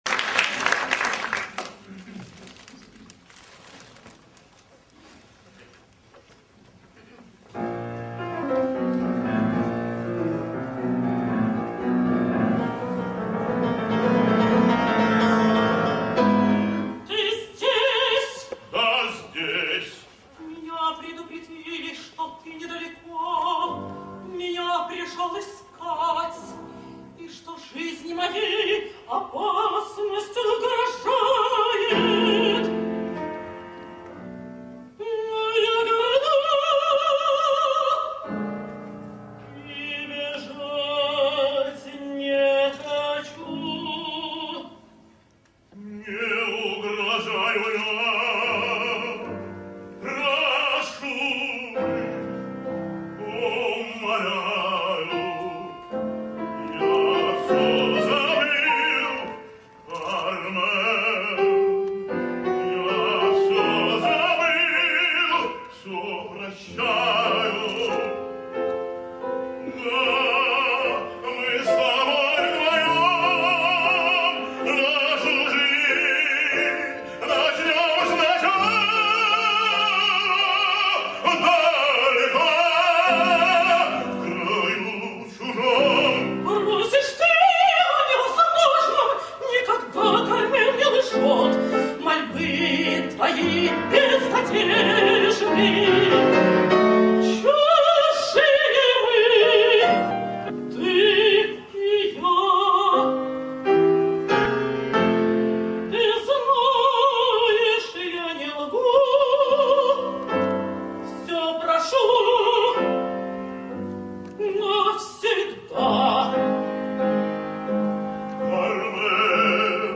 While Eastern Europe in general and Russia in particular have beyond doubt maintained quality in operatic singing for a much longer time than the rest of the world (and in some cases, they've maintained it even up to our days), it's the proof that there is no such thing as a Promised Land of opera, not even in Russia, if a positively horrible shouter and pusher like Osipov could become of the country's most famous tenors...
Vjacheslav Osipov singsCarmen: